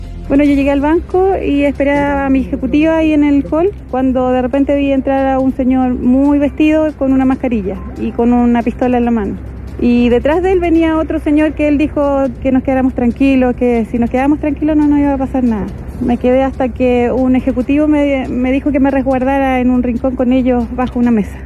Una testigo del asalto que afectó a una sucursal del banco BBVA en el Mall Plaza Maule en Talca relató lo sucedido a Radio Bío Bío.